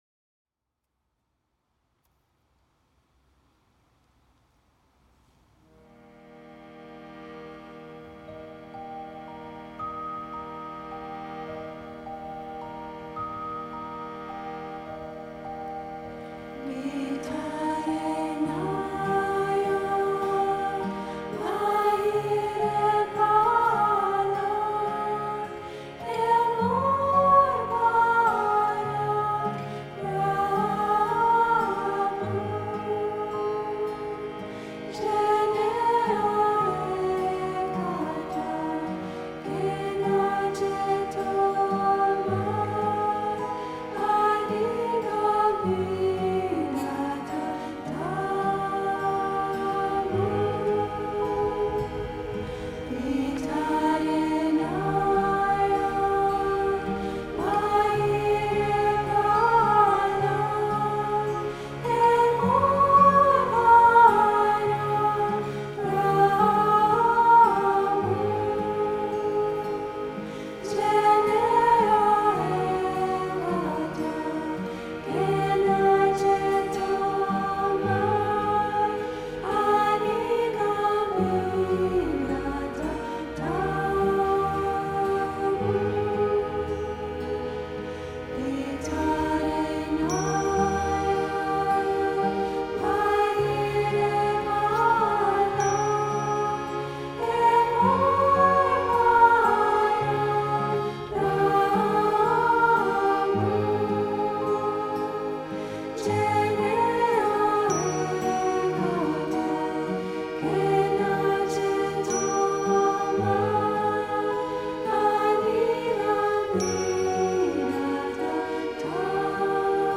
instrumental and vocal ensemble
soulful arrangements
featuring harmonium, glockenspiel, guitar and percussion.